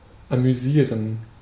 Ääntäminen
IPA: /amyˈziːʁən/ IPA: [ʔamyˈziːɐ̯n]